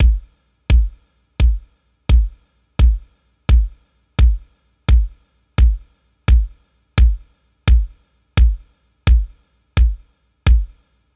Index of /DESN275/loops/Loop Set - Futurism - Synthwave Loops
BinaryHeaven_86_Kick.wav